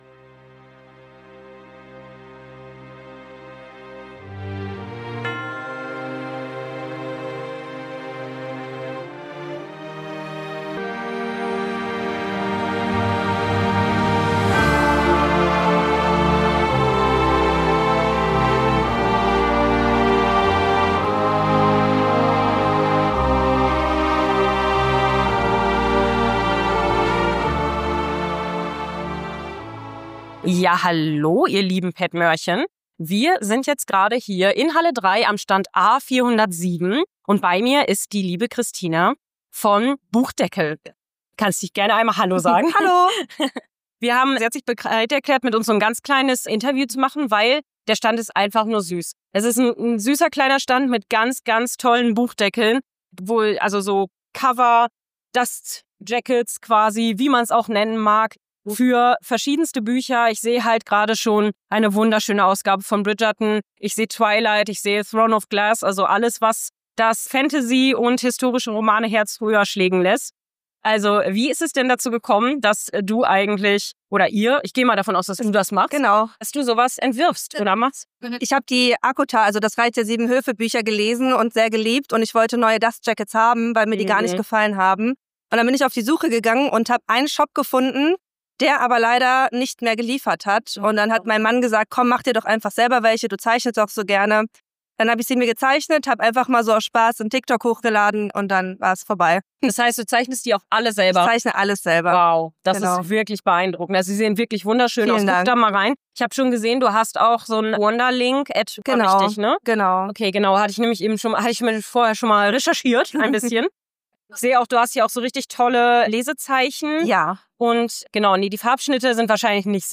Beschreibung vor 1 Jahr Die Messe neigt sich dem Ende aber wir haben nochmal zwei sehr interessante Interviews für euch geführt.